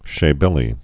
(shā-bĕlē, shə-) or She·be·le (shē-bālē, shə-)